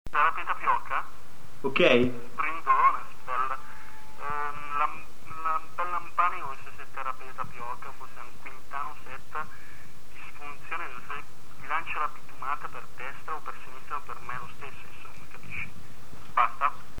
R.: ecco qua il nostro album sperimentale.